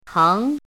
怎么读
téng
teng2.mp3